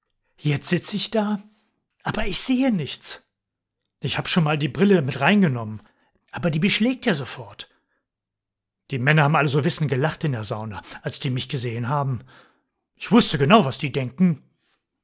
Allein in der Sauna – Komödie